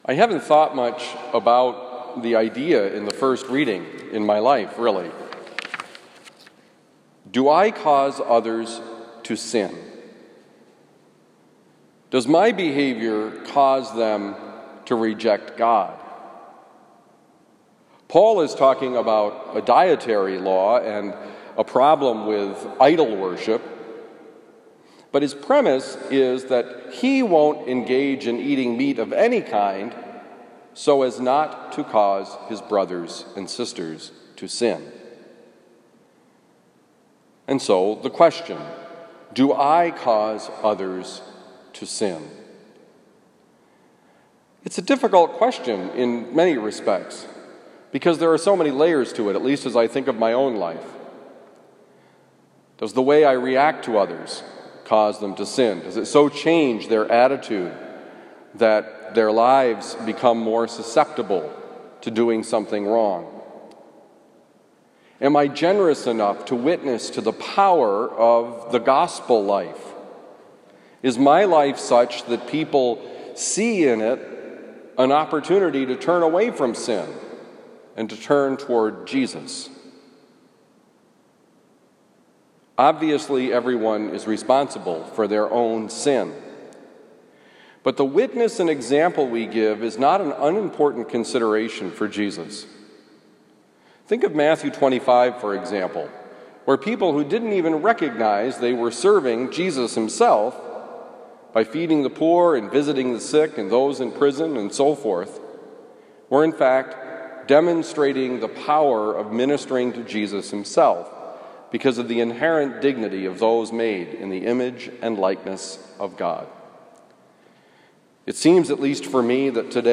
Homily for September 10, 2020
Given at Christian Brothers College High School, Town and Country, Missouri